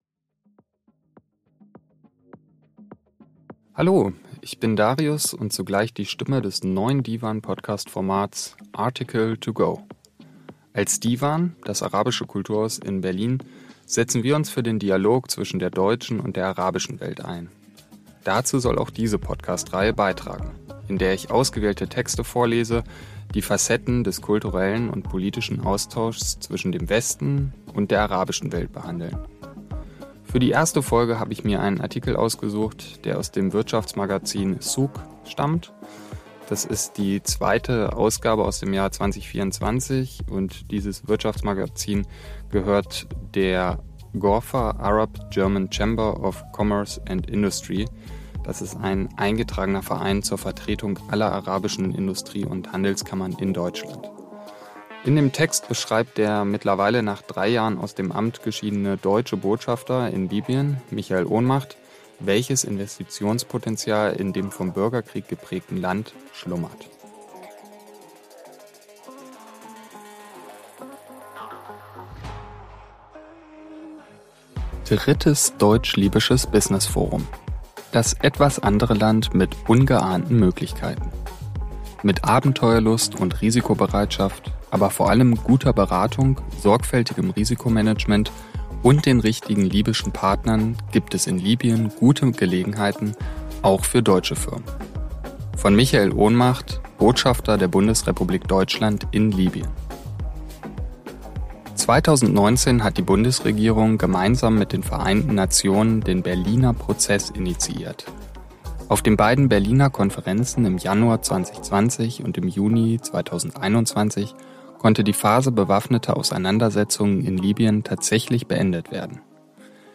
Dazu soll auch diese Podcast-Reihe „Article to go” beitragen, in der ausgewählte Texte vorgelesen, die Facetten des kulturellen und politischen Austauschs zwischen dem „Westen“ und der arabischen Welt behandeln.